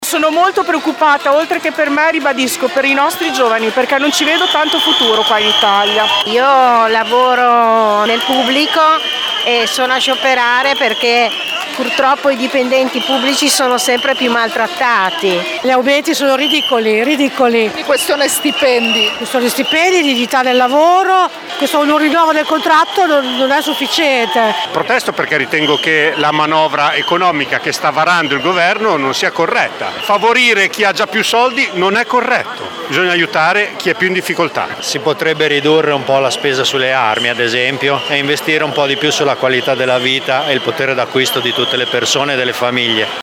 Ecco qualche voce di chi ieri era al corteo:
vox-sciopero.mp3